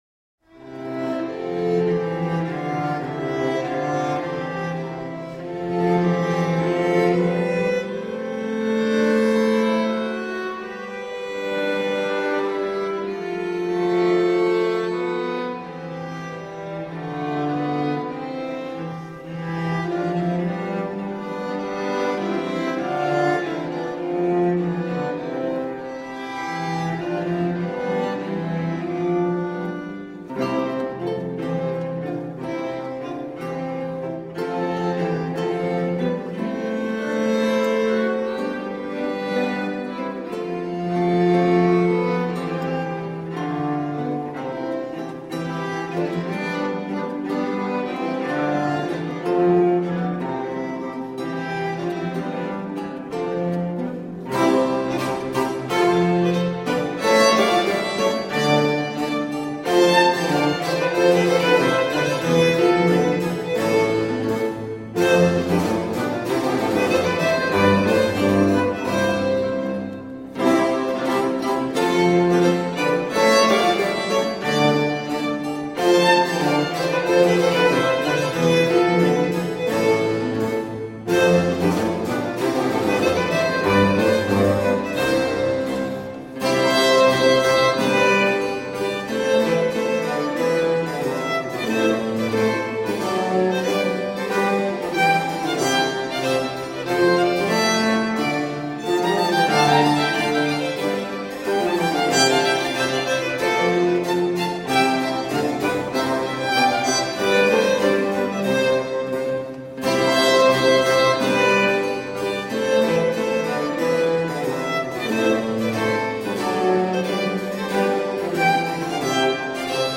17th century baroque ensemble.
Classical, Orchestral, Baroque, Instrumental
Organ, Violin